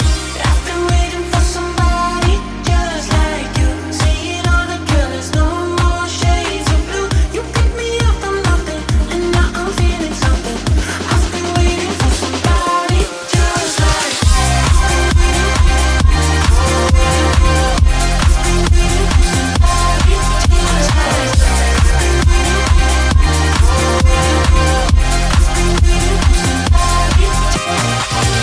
Genere: pop,dance,deep,disco,house.hit